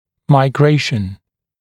[maɪ’greɪʃn][май’грэйшн]миграция, смещение, перемещение